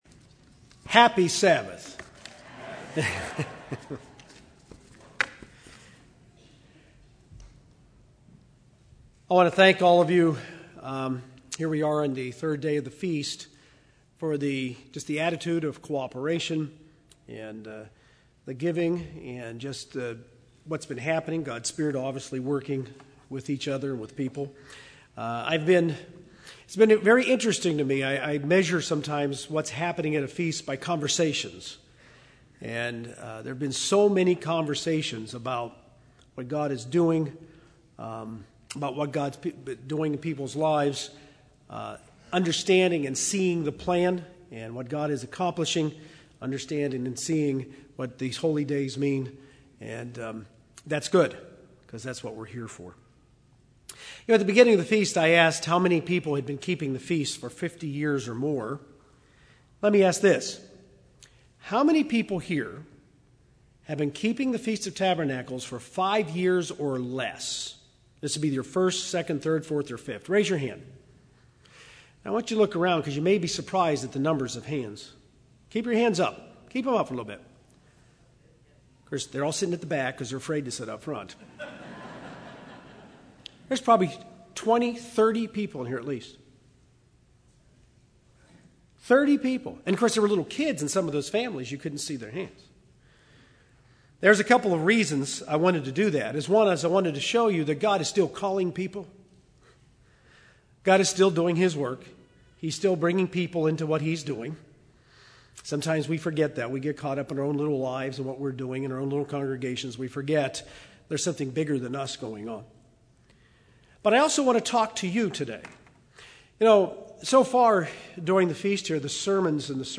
This sermon was given at the New Braunfels, Texas 2013 Feast site.